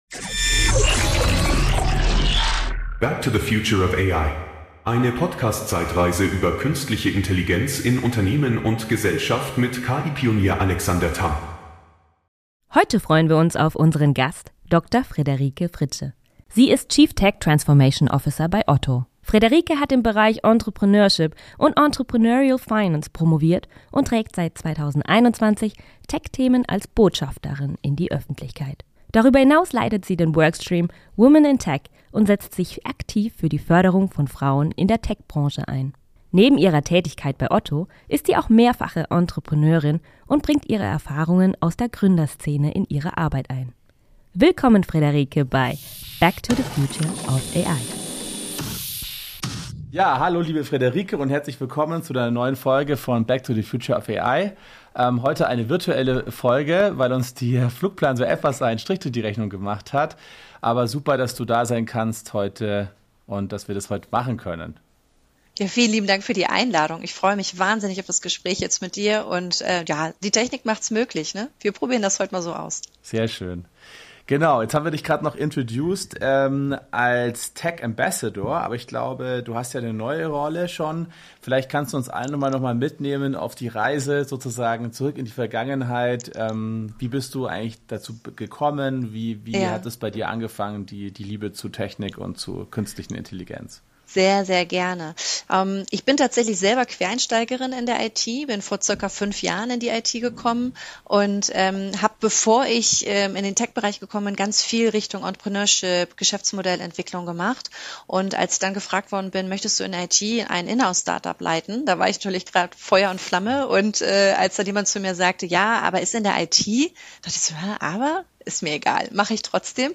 Die beiden unterhalten sich über Herausforderungen und Chancen von KI sowie die Bedeutung von Diversität in Teams.